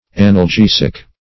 analgesic - definition of analgesic - synonyms, pronunciation, spelling from Free Dictionary
analgesic \an`al*ge"sic\ ([a^]n`[a^]l*j[=e]"z[i^]k or